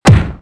B_COUP_ASSOME.mp3